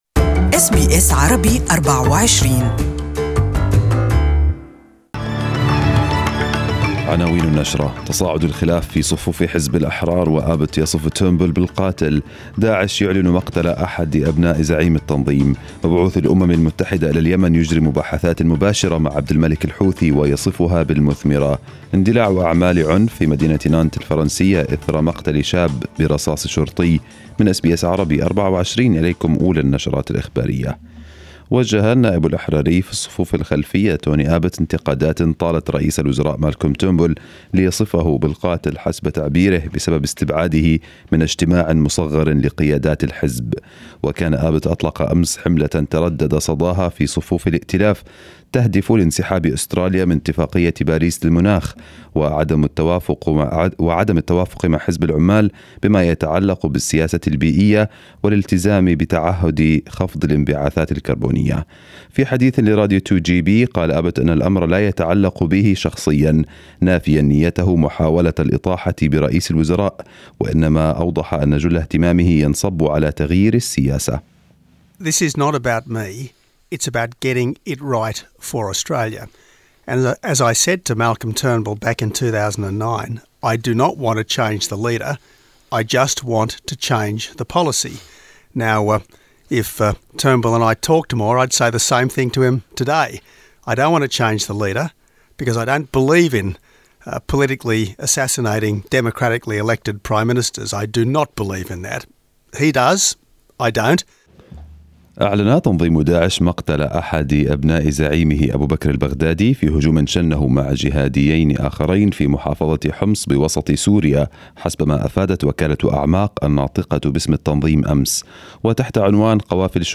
Arabic News Bulletin 05/07/2018.